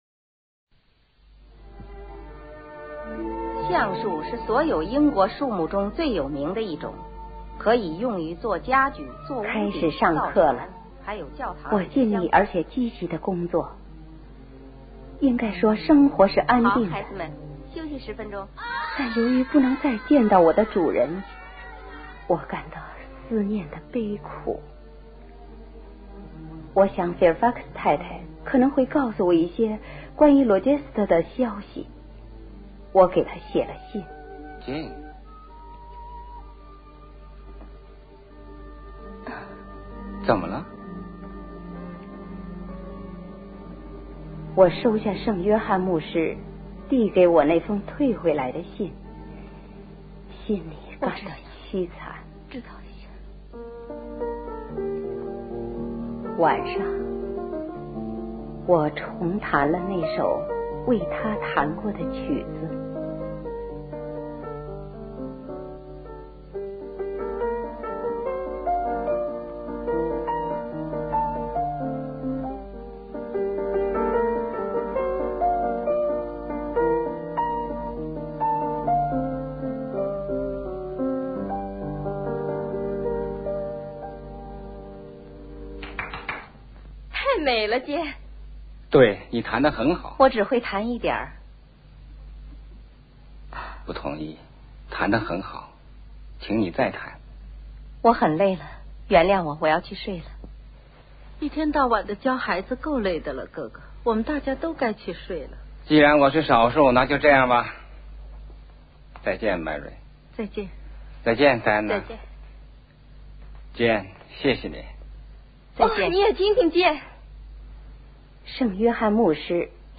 邱岳峰塑造的罗切斯特，或愤懑，或柔情，或倦怠，或狂暴，无不让人动容。而李梓演绎的简爱在不愠不火的声调里展现出细腻的情感变化，或温婉，或坚定，也在更深层次上丰富了简爱人物的形象。他们的声音有表情，有形象，有情感，一寸一寸都是鲜活的。